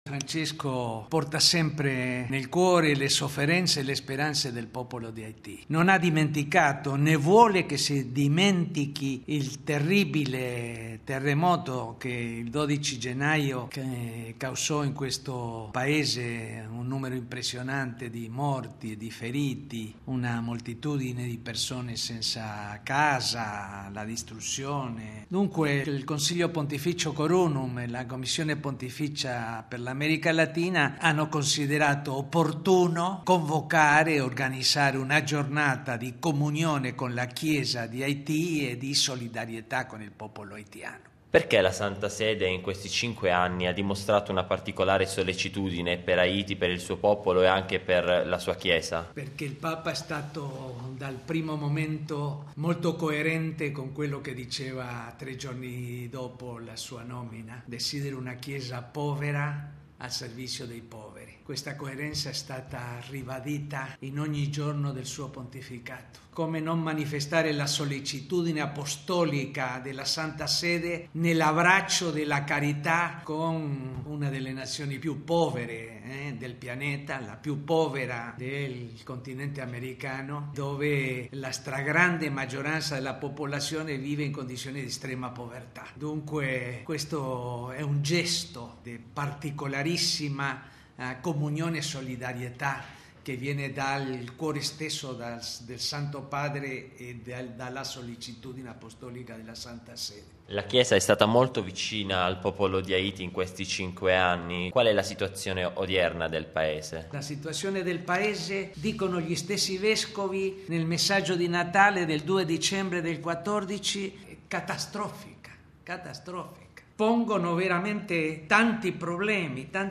Papa Francesco non dimentica Haiti: è quanto afferma Guzmán Carriquiry Lecour, segretario della Pontificia Commissione per l’America Latina, in occasione del quinto anniversario del catastrofico terremoto che il 12 gennaio del 2010 portò morte e distruzione nel Paese. Ascoltiamolo al microfono di